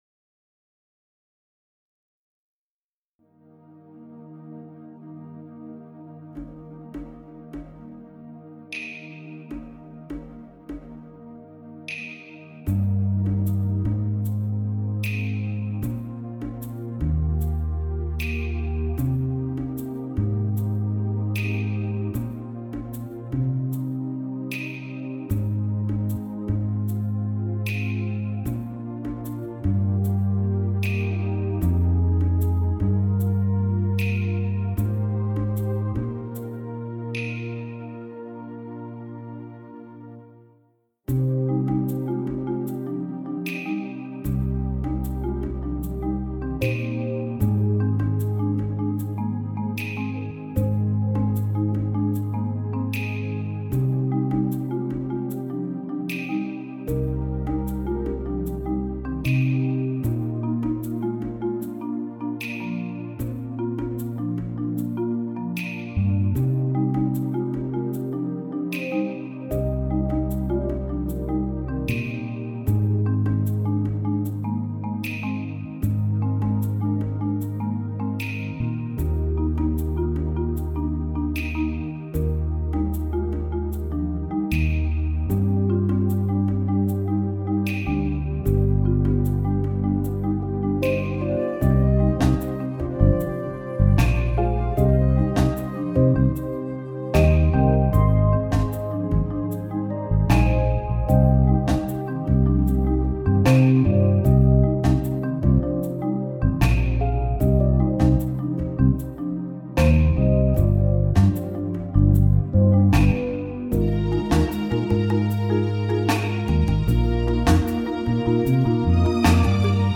Shadowland-Backing.mp3